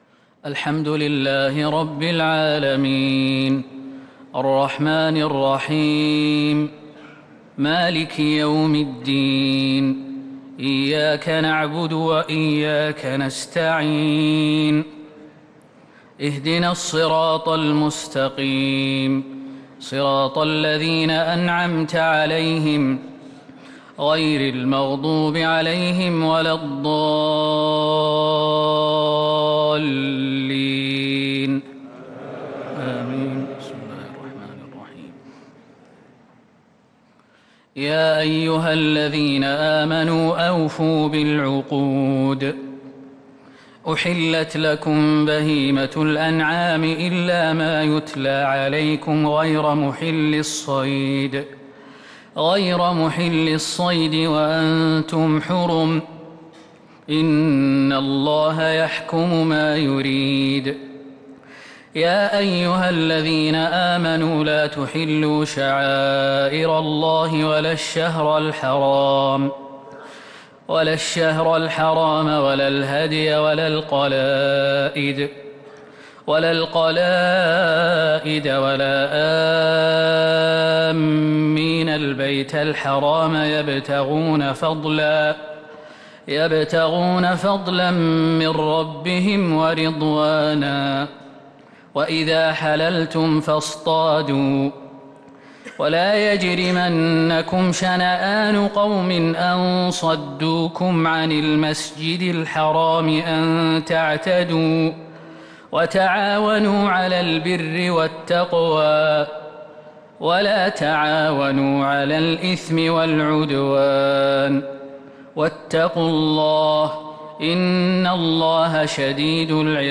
تراويح ليلة 8 رمضان 1443هـ من سورة المائدة {1-40} Taraweeh 8st night Ramadan 1443H Surah Al-Maidah > تراويح الحرم النبوي عام 1443 🕌 > التراويح - تلاوات الحرمين